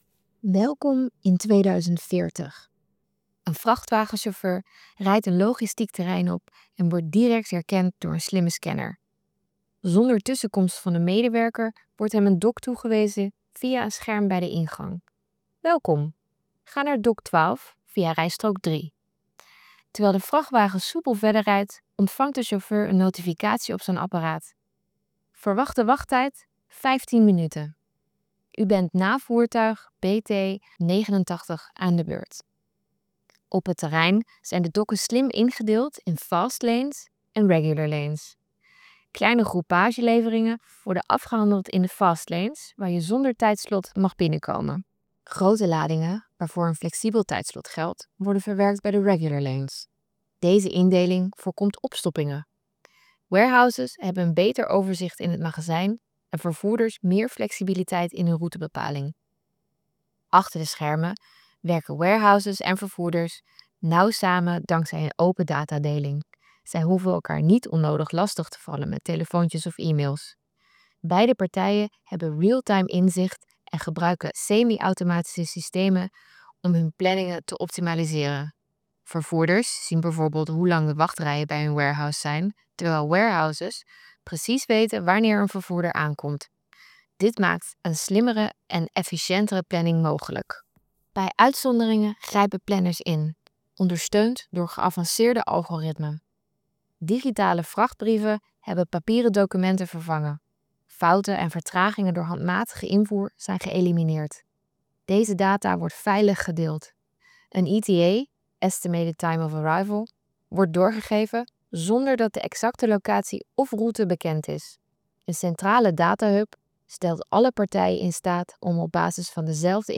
Op basis van de opgehaalde behoeften van warehouses en vervoerders creëerde Muzus in co-creatie een audioverhaal waarin een